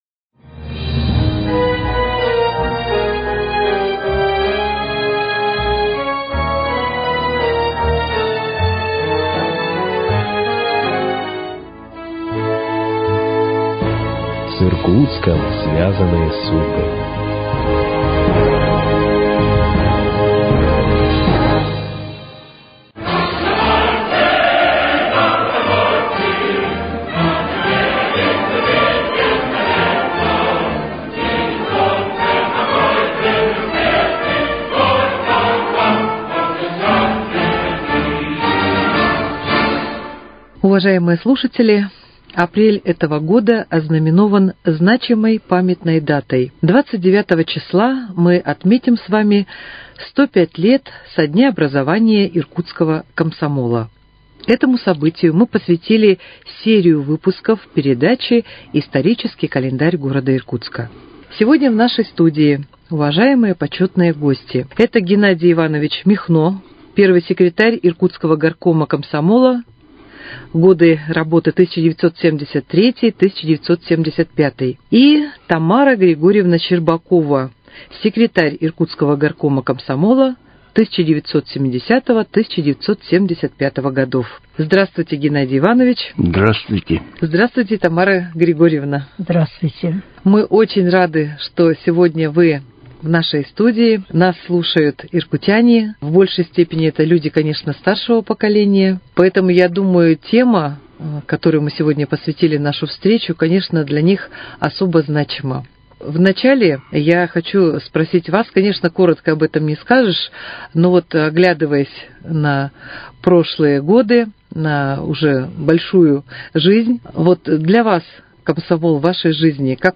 участники передачи члены Иркутской региональной общественной организации – движения «Ветераны комсомола»